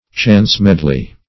Chance-medley \Chance"-med`ley\, n. [Chance + medley.]